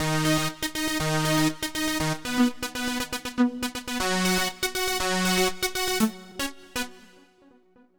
Power Pop Punk Keys 02b.wav